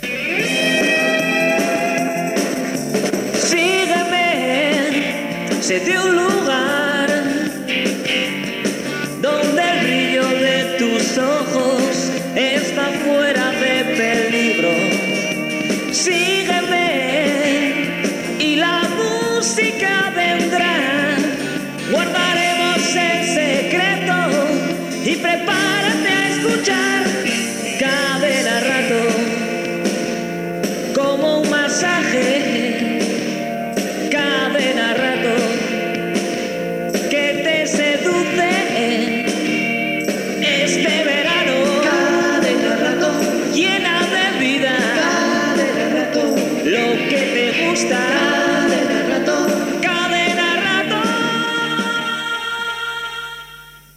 Cançó d'estiu promocional